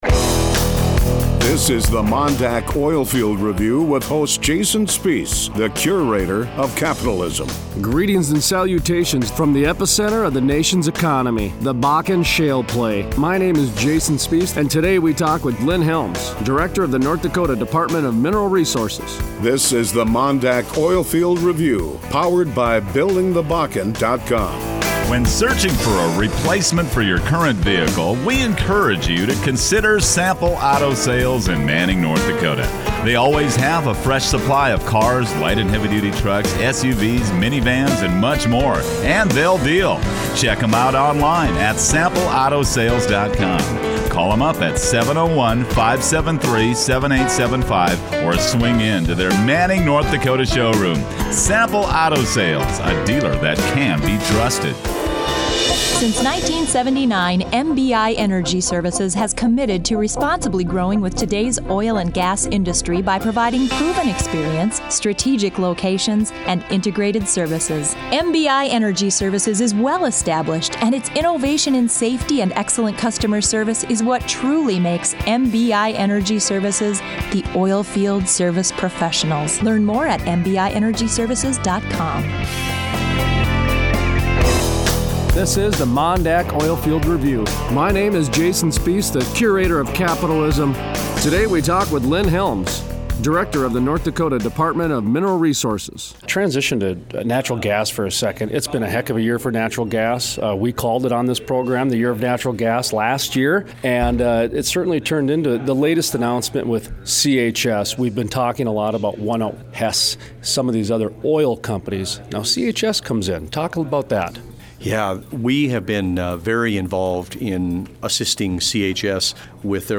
Tuesday January 6, 2015 Interview: Lynn Helms, director, North Dakota Department of Mineral Resources